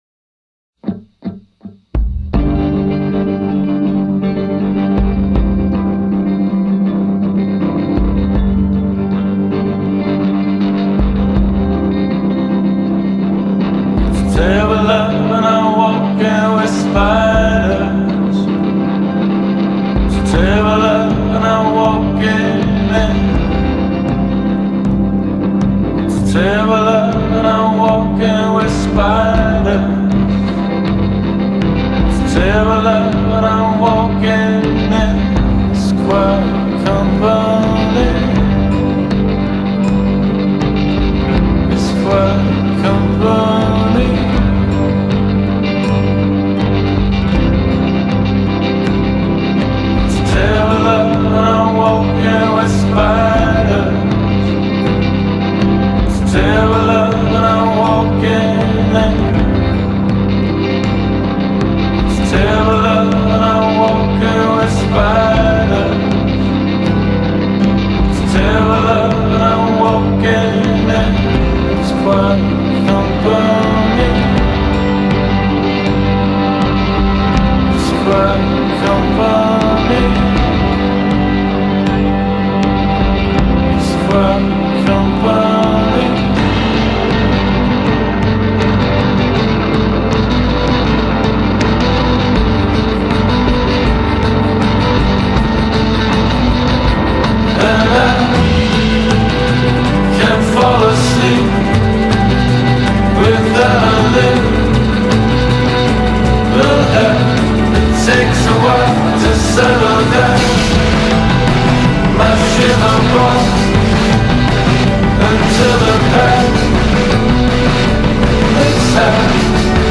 Today’s Emo
The piano, the builds and breaks, the lyrics